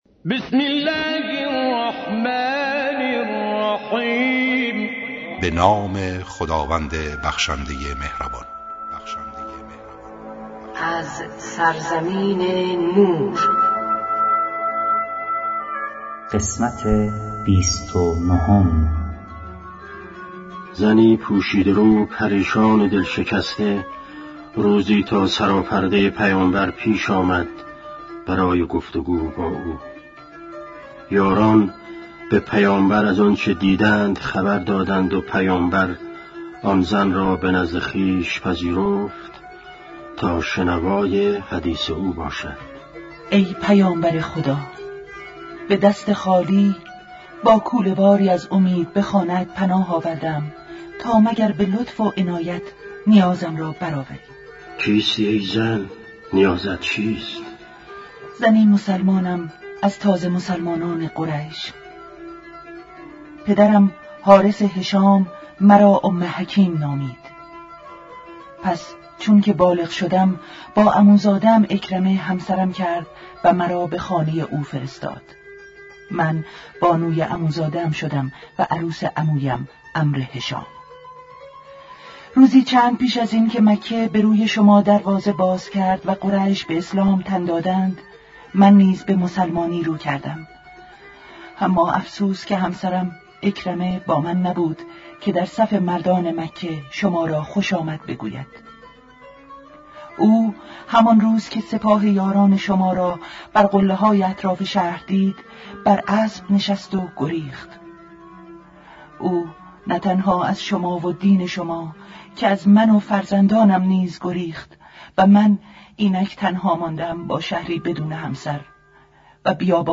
با اجرای مشهورترین صداپیشگان، با اصلاح و صداگذاری جدید
کتاب گویا, کیفیت بالا